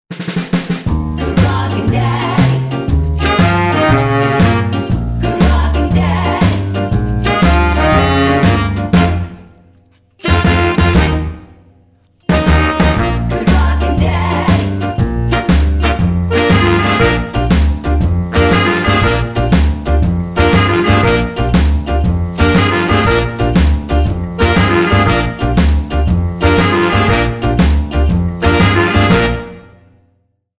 --> MP3 Demo abspielen...
Tonart:Bb mit Chor